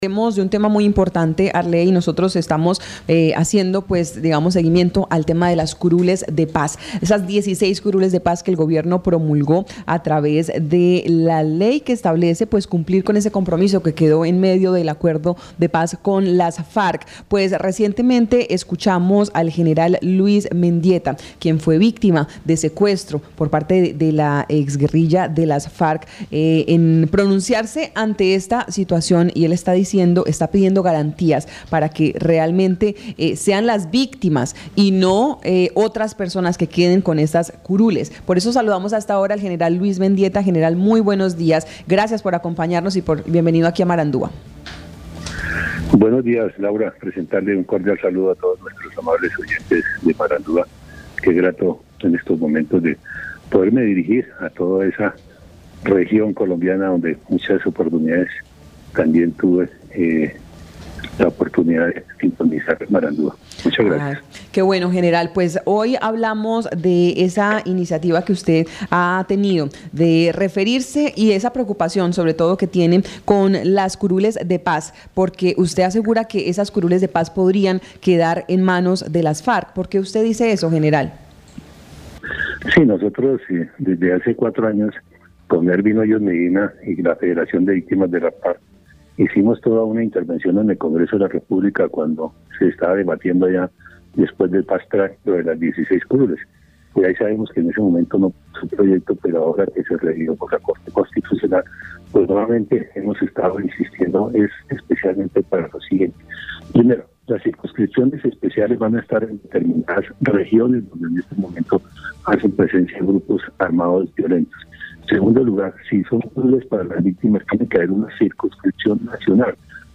En entrevista con Marandua Noticias el general (r) Luís Herlindo Mendieta, quien fuera víctima por más 12 años de secuestro por parte de la guerrilla de las extinta Farc, manifestó su preocupación y al interior de la Asociación de Víctimas de las Farc, por la distribución de las 16 curules de paz.